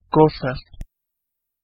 Ääntäminen
IPA: [stɔf]